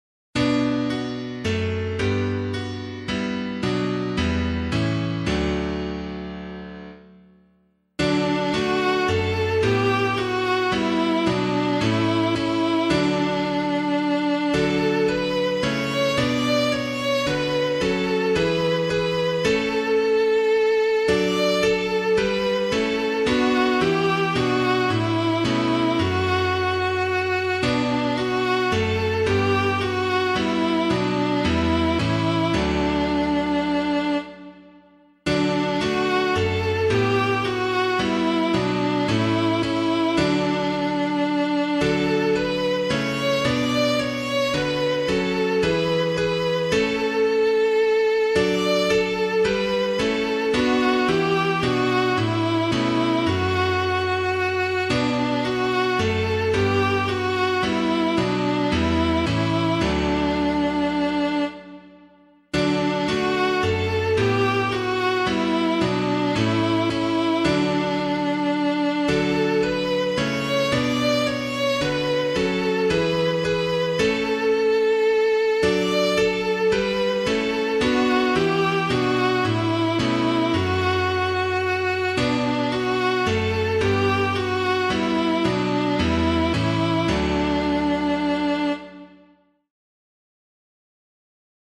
Hymn suitable for Catholic liturgy